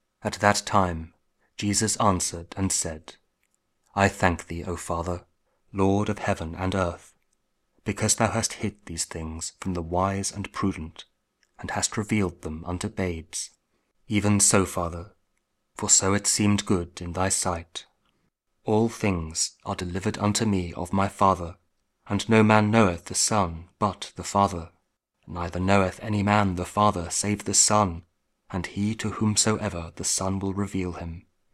Matthew 11: 25-27 – Week 15 Ordinary Time, Wednesday (King James Audio Bible KJV, Word Aloud Spoken Word)